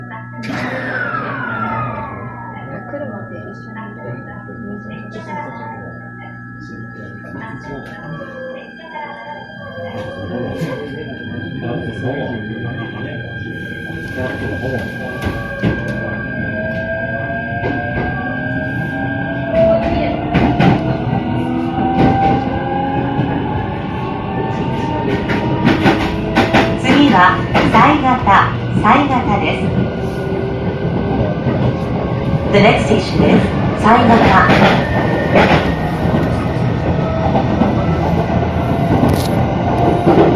210407 新潟・上越
[E129（モハ）] 黒井→犀潟 走行音